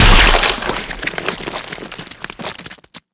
anomaly_body_tear_1.ogg